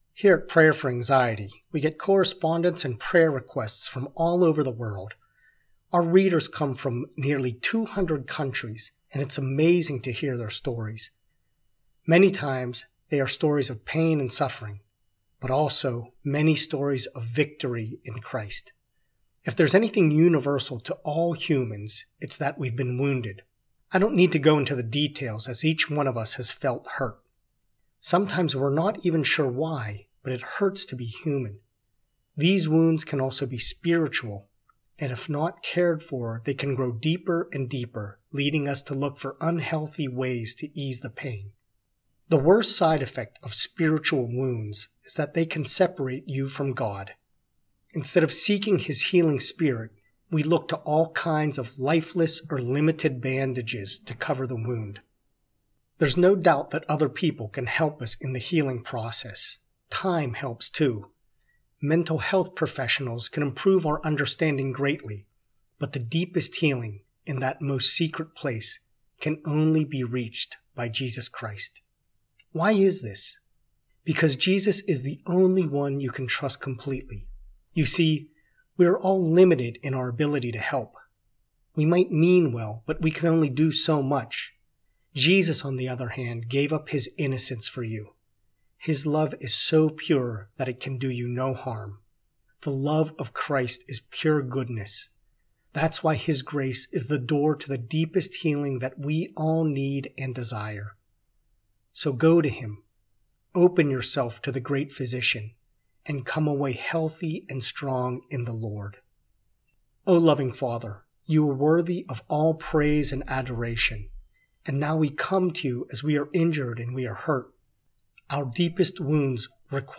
prayer-for-spiritual-healing.mp3